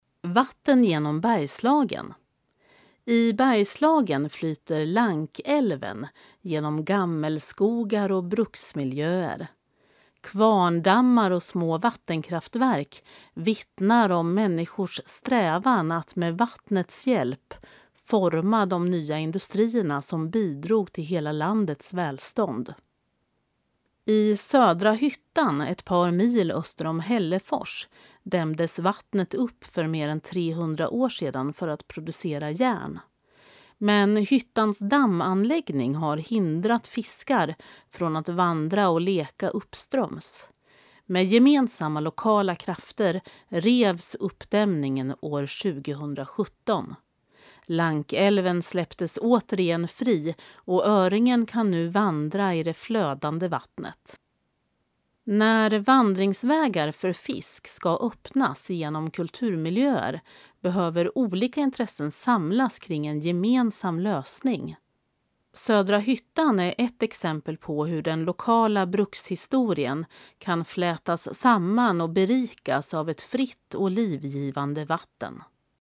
Klicka här för att lyssna till texten, inläst av en professionell uppläsare